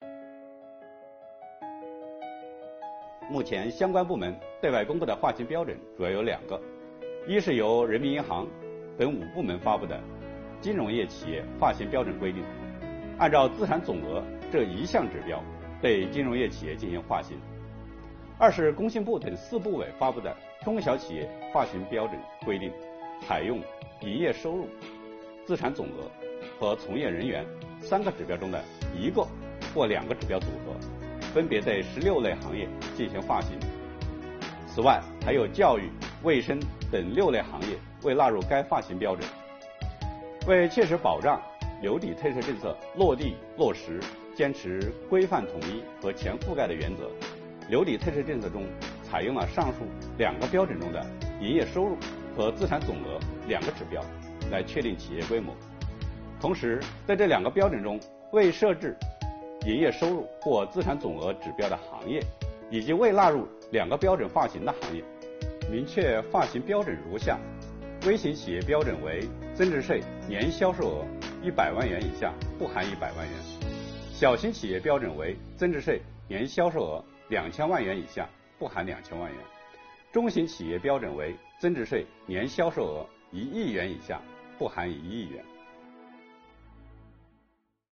国家税务总局货物和劳务税司副司长刘运毛担任主讲人，详细解读了有关2022年大规模留抵退税政策的重点内容以及纳税人关心的热点问题。